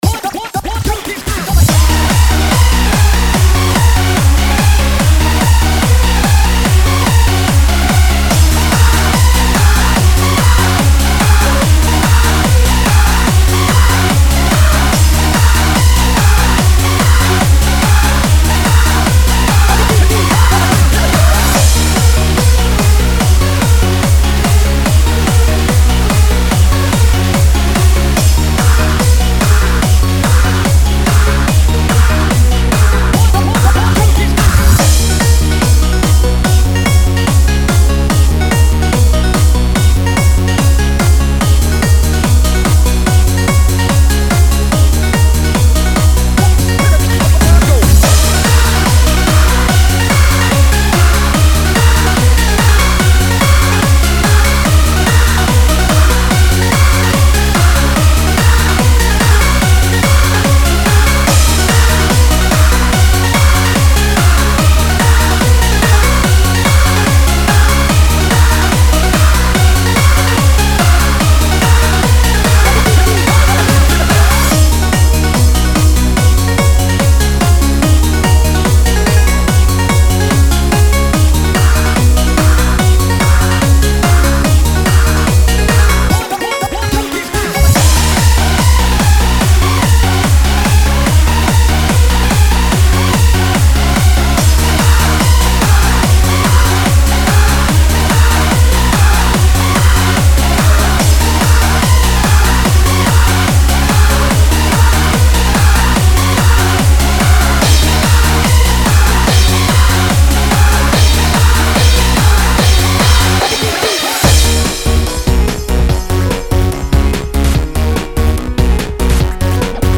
quite repetitive at times
145 bpm
techno trance handsup handzup oldschool rave dance remix